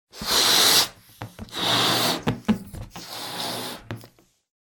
balloonblow.wav